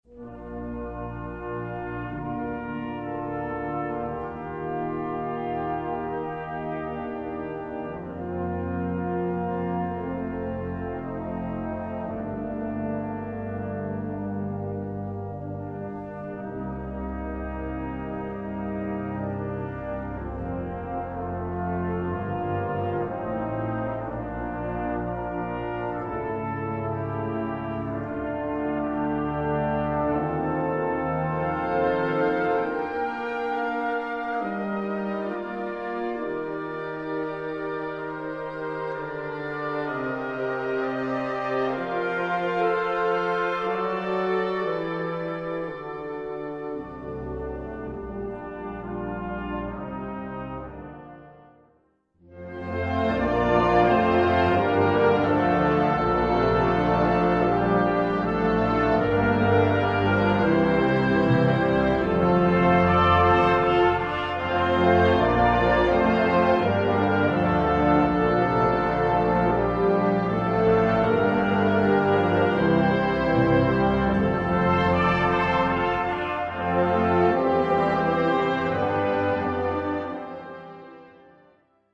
Gattung: Kirchenlied
Besetzung: Blasorchester